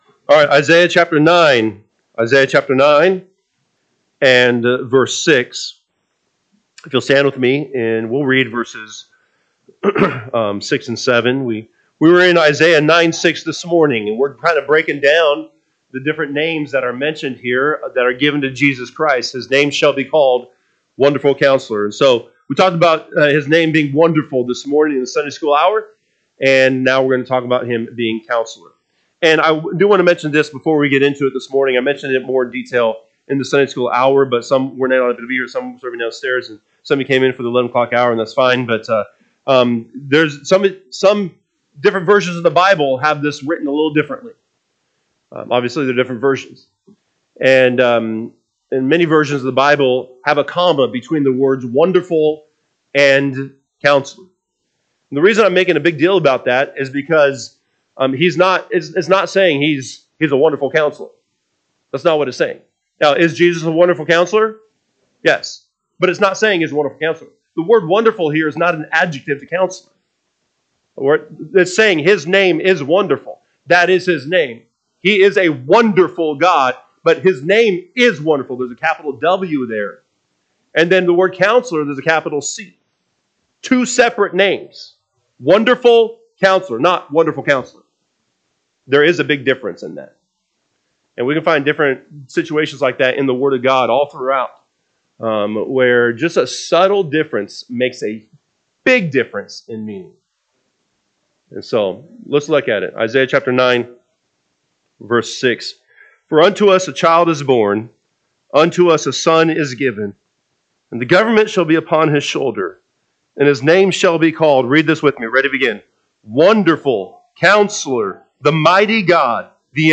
Sunday AM Bible Study